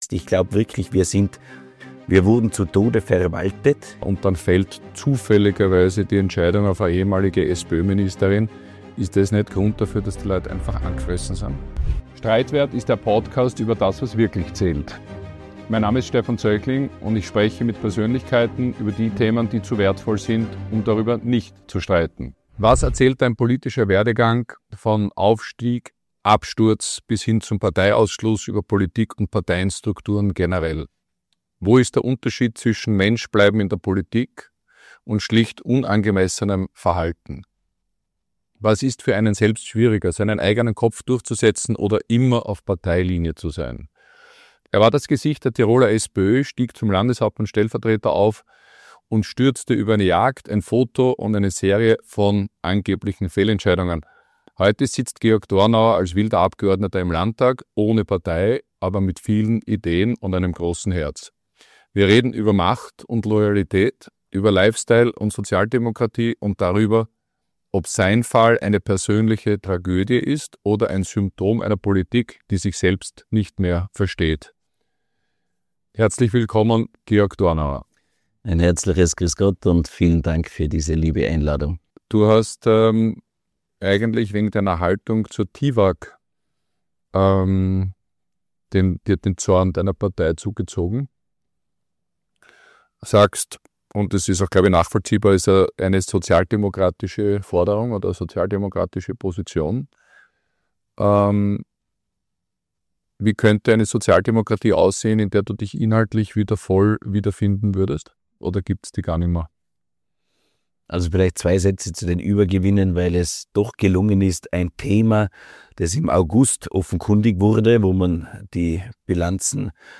In Teil 2 spricht Georg Dornauer über die Übergewinne der TIWAG, Sozialstaat und Leistungsprinzip, Bürokratie als Standortbremse und die Frage, ob Parteiapparate Reformen überhaupt noch zulassen. Es geht um Mindestsicherung und Missbrauch davon, Verwaltungsträgheit, Postenschacher, Verantwortung in der Politik und um die Möglichkeit einer eigenen politischen Bewegung. Ein Gespräch über Pragmatismus statt Ideologie und darüber, warum viele Menschen nicht politikverdrossen, sondern politikerverdrossen sind.